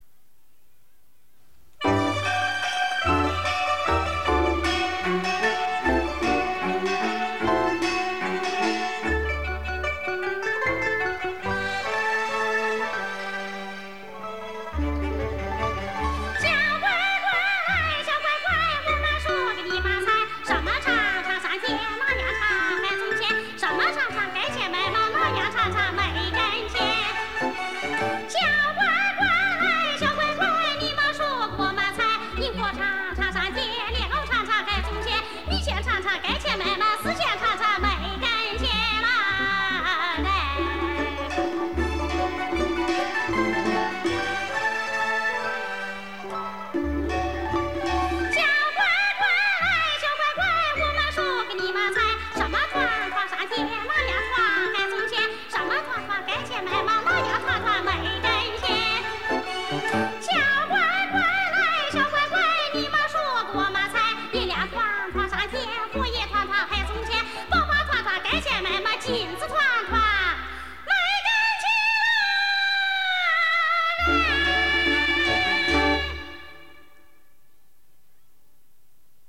云南民歌